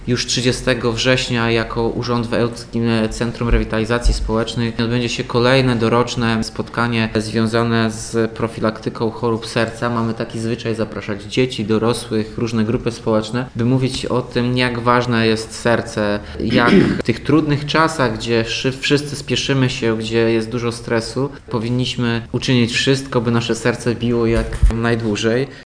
– Podsumowaniem akcji profilaktycznej będzie VI Forum Kardiologiczne „Ełk – tu bije moje serce” – mówi Tomasz Andrukiewicz, prezydent miasta.